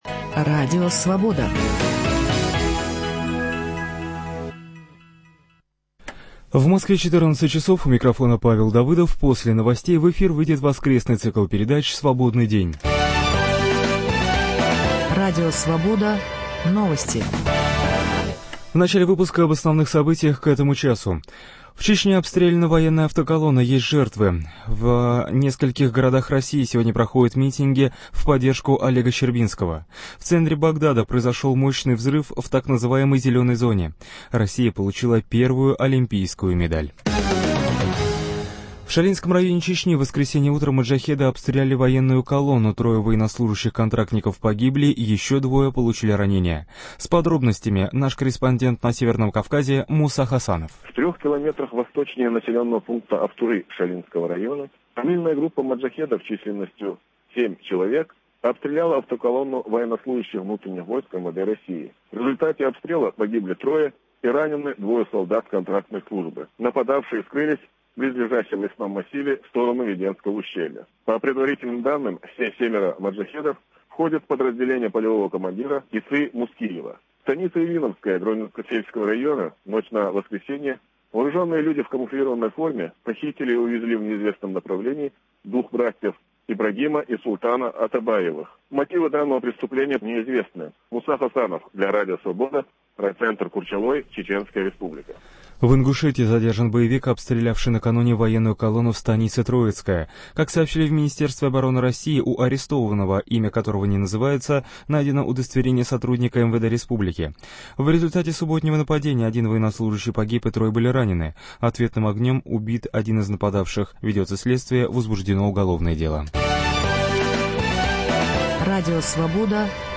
По телефону из Беслана
в студии – лидер движения «Демократический Союз» Валерия Новодворская и депутат Государственной Думы России Владимир Рыжков.